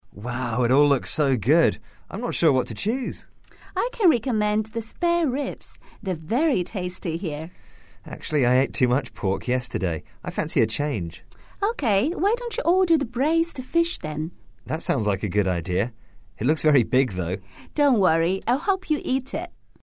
英语初学者口语对话第34集：不知该店什么/换个口味吧